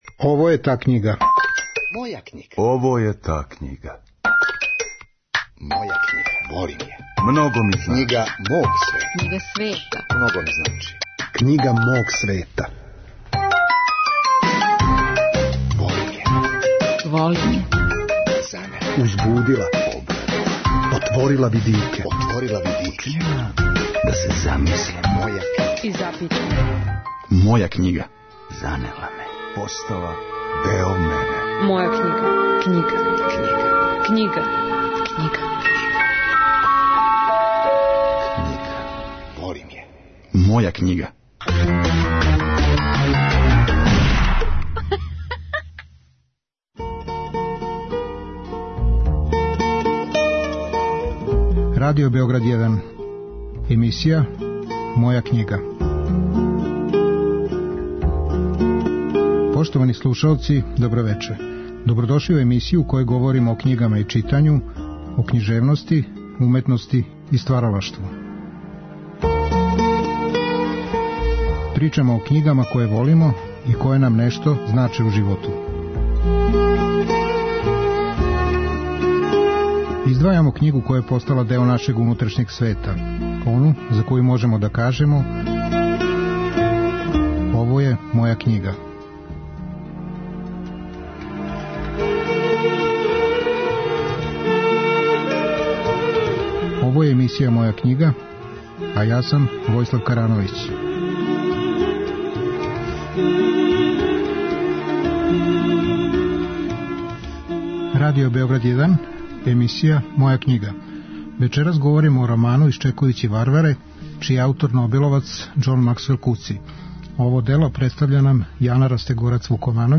Емисија о књигама и читању, о књижевности, уметности и стваралаштву. Гости су људи различитих интересовања, различитих занимања и професија. Сваки саговорник издваја књигу коју воли, ону која му је посебно значајна и за коју може да каже: ово је моја књига.